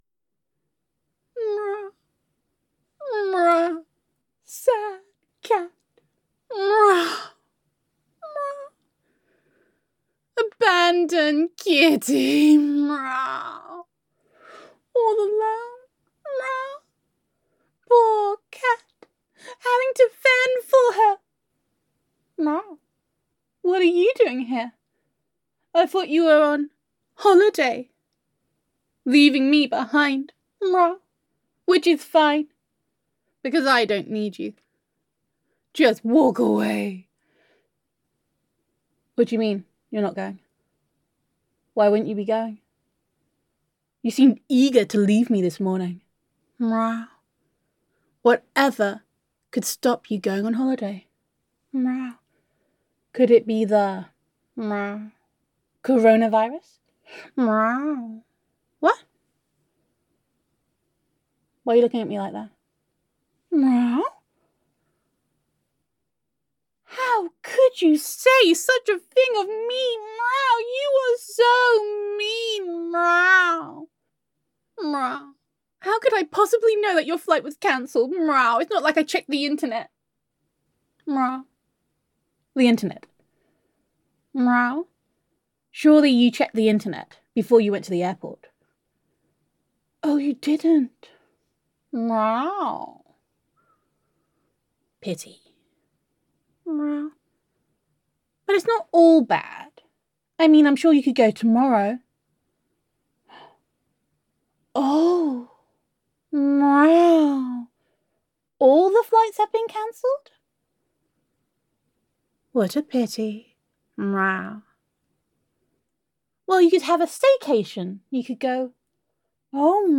[F4A] Corona and Drama Cat [Cancelled Holiday][You Meow and You Can’t Go out][Catitude][Mrau][Unloved Kitty][Gender Neutral][Neko Roleplay]